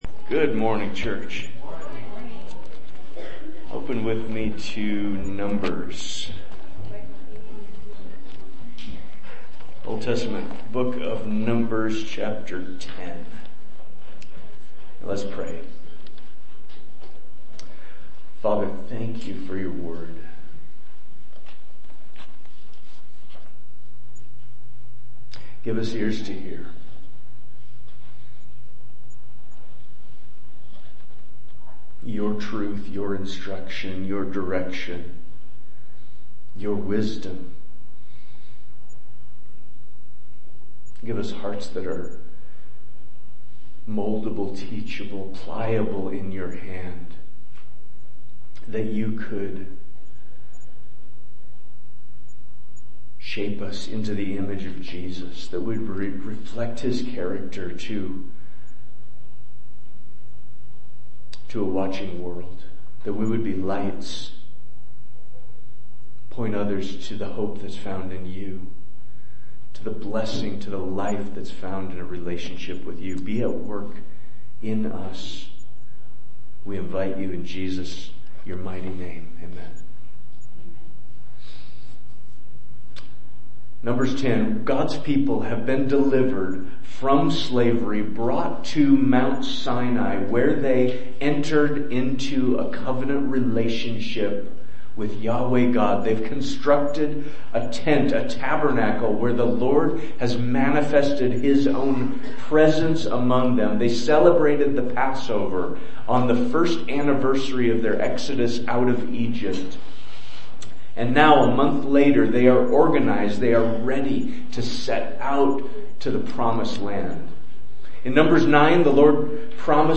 Sermon Manuscript